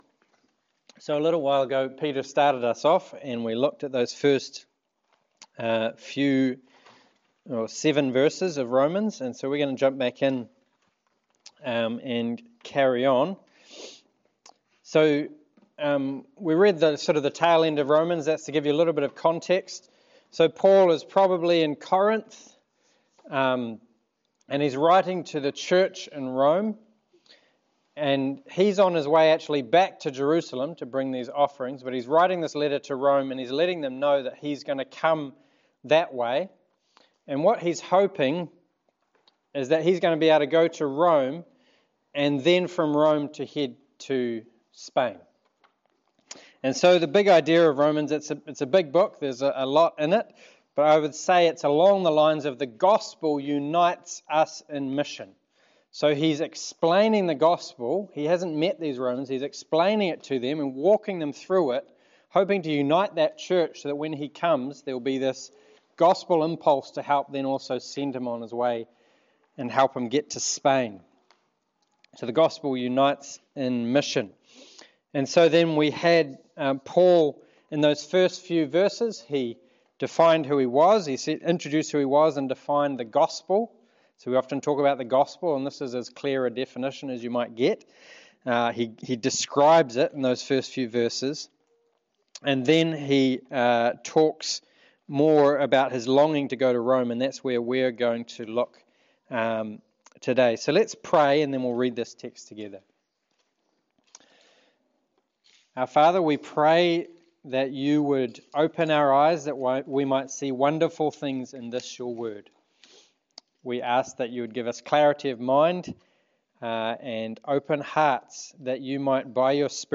Romans 1:8-15 Service Type: Sermon Individualism is the default mode of thinking in the west today.